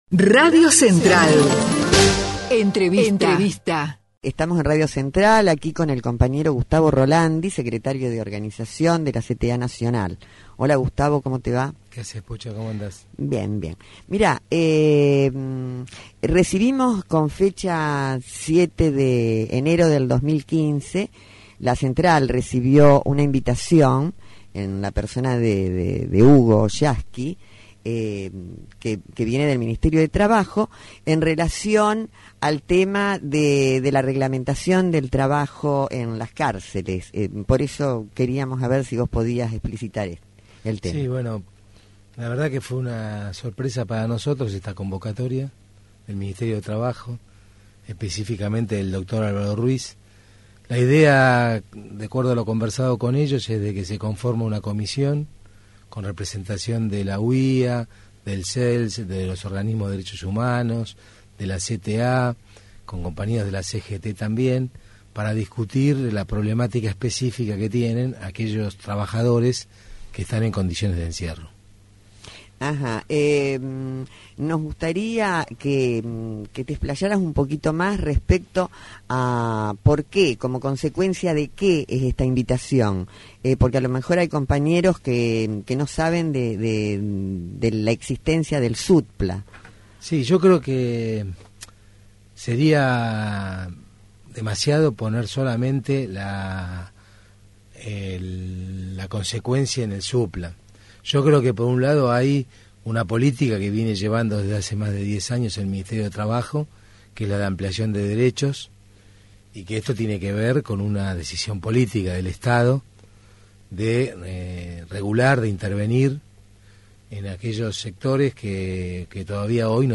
estuvo en Radio Central, ampliando el tema de la convocatoria realizada por el Ministerio de Trabajo a nuestra Central, vinculada con el trabajo en las cárceles.